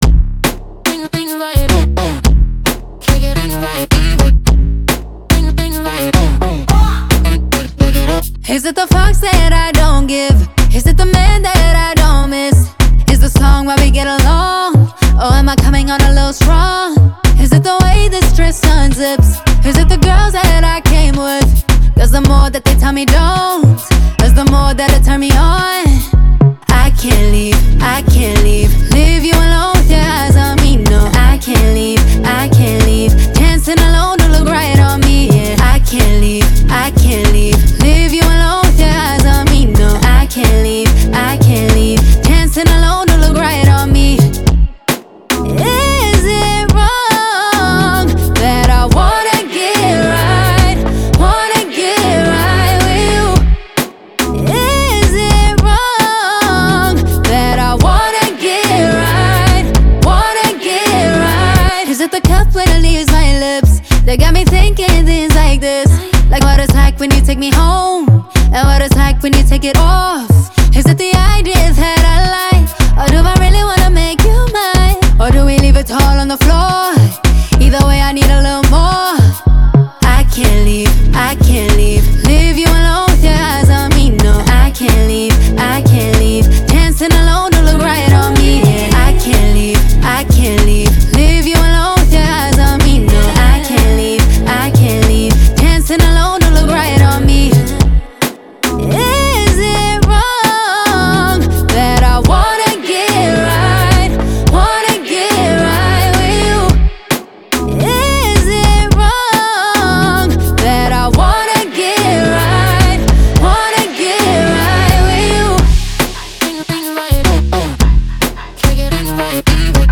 sultry vocals, Afro-pop rhythms
Afro-pop